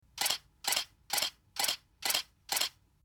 Camera Shutter Click
Camera_shutter_click.mp3